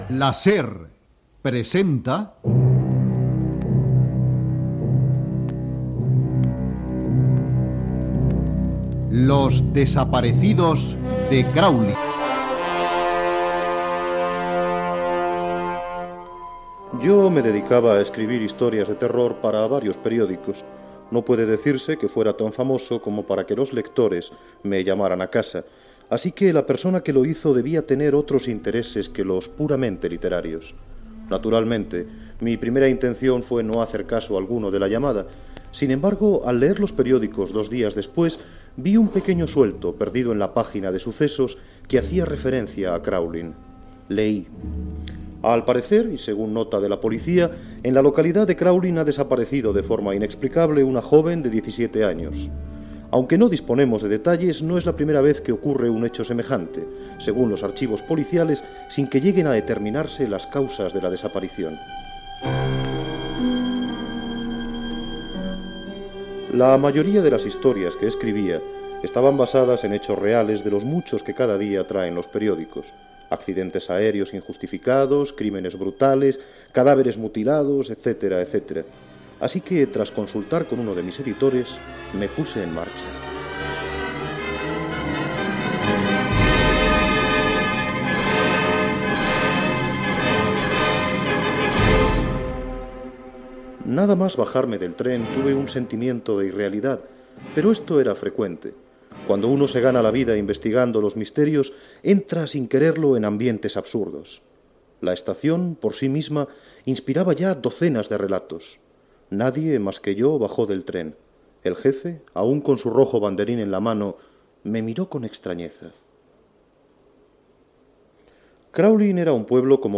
Radionovela
Ficció